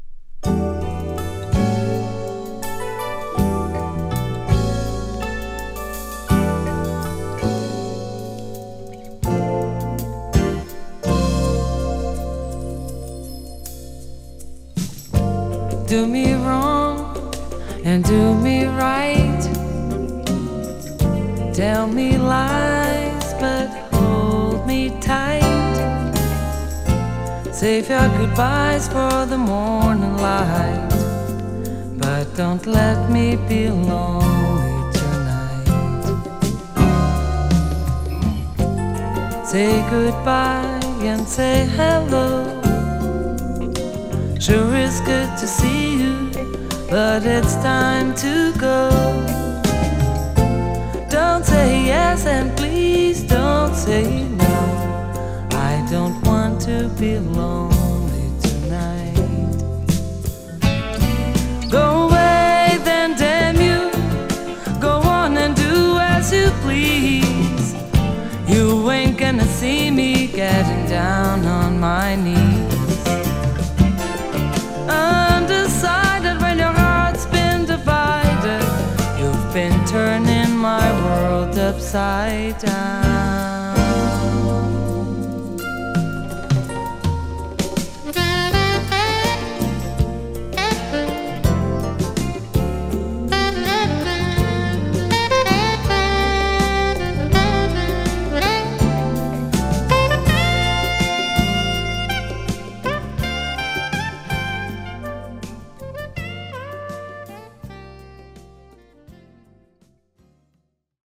ブラジル出身のシンガー